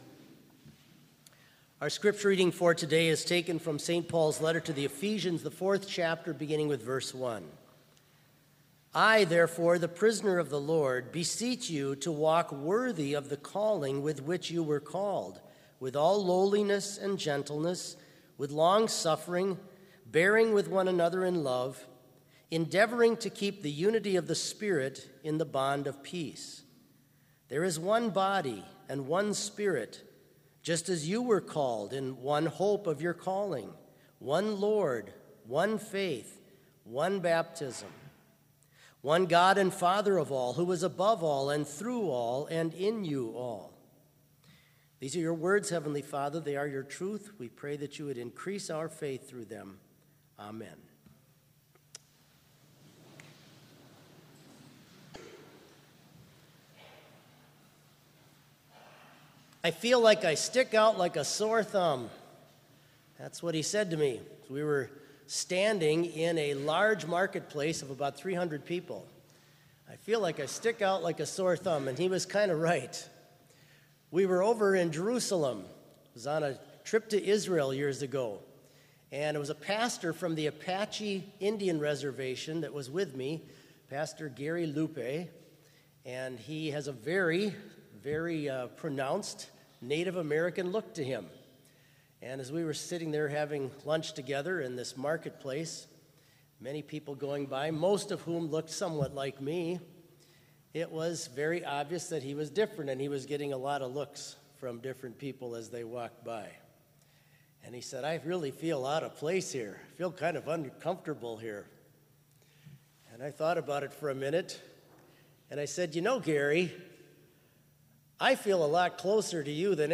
Complete service audio for Chapel - September 23, 2019